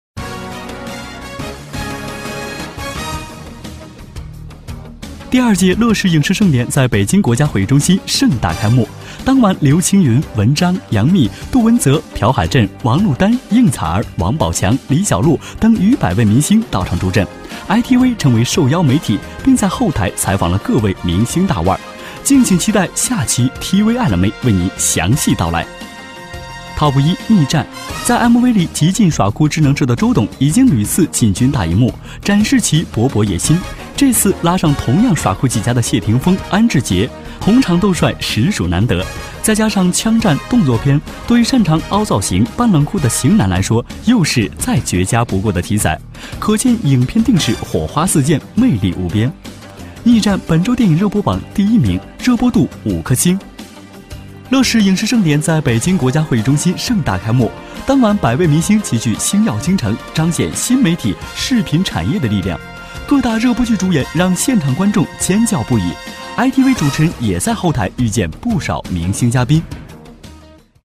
广告配音--声音作品--海滨声音艺术学院